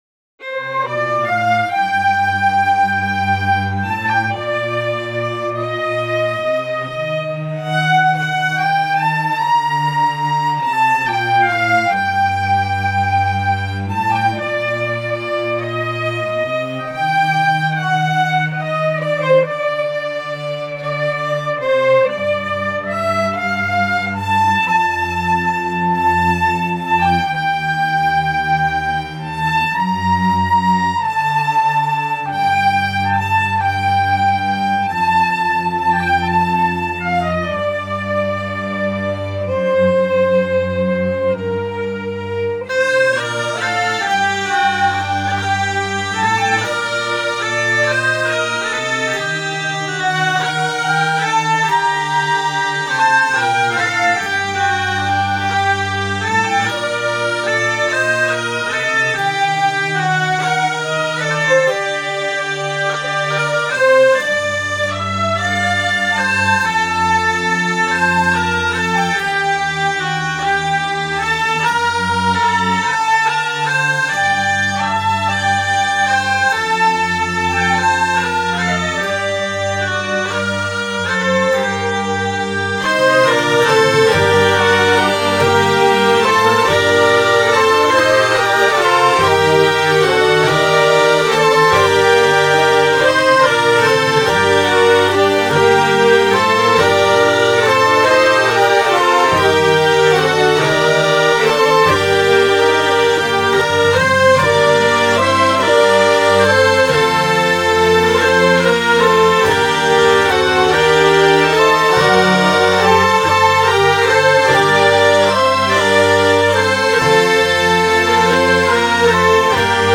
Trad. Nivernais collecte Achille MILLIEN – arrangement Dominique FORGES
dans l’album Sourires de l’Ensemble de Musiques traditionnelles de Nevers